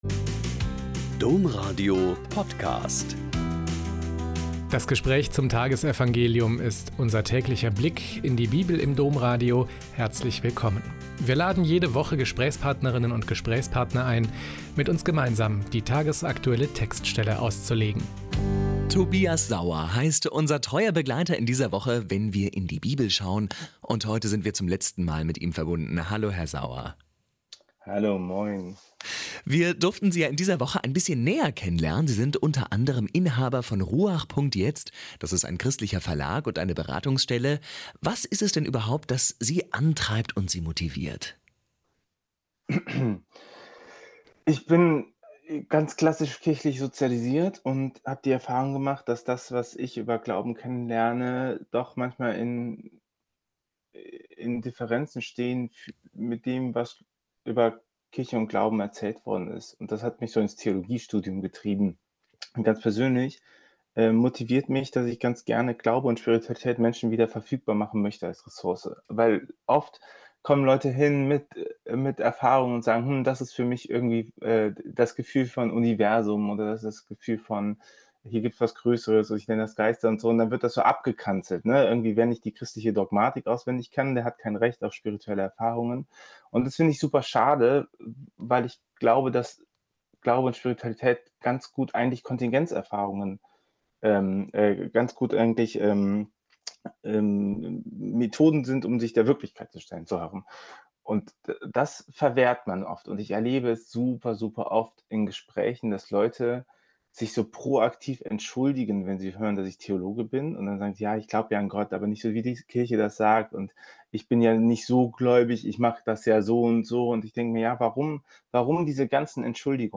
Mt 17,9a.10-13 - Gespräch